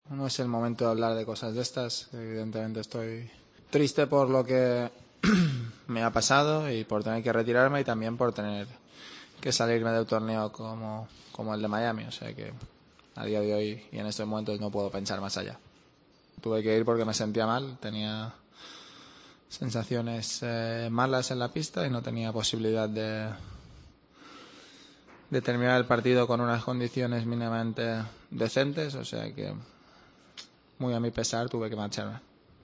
Rafa Nadal explicó en sala de prensa los motivos de su abandono en Miami.